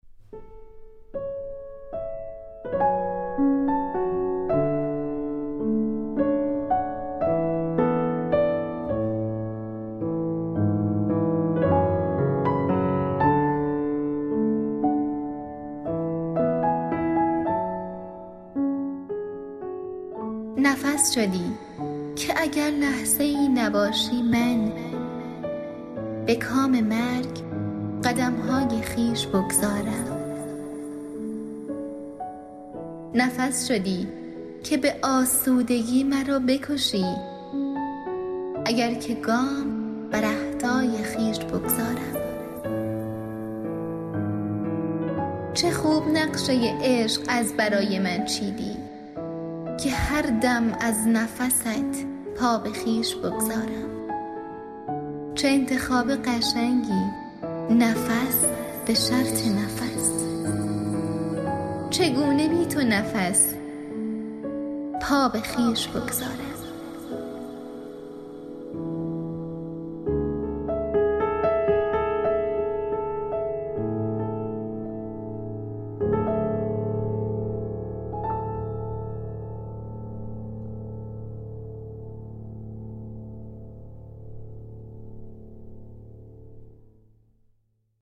دکلمه
میکس و مسترینگ